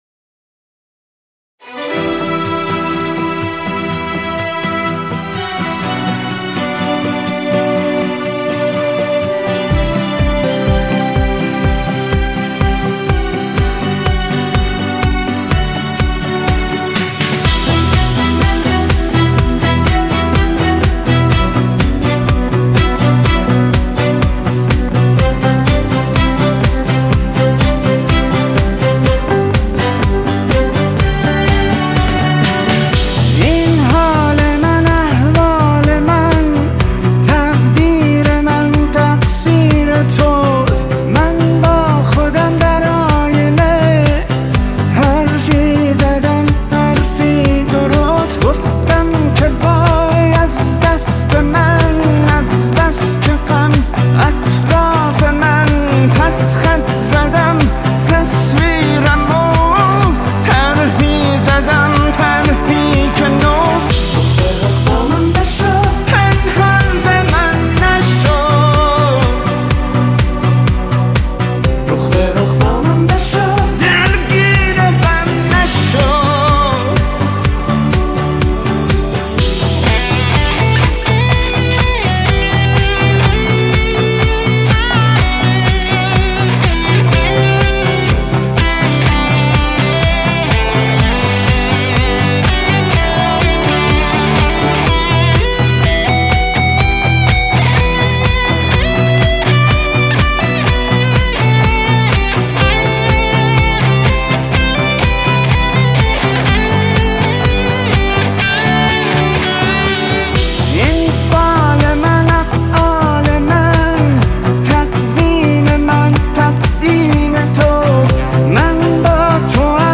آهنگهای پاپ فارسی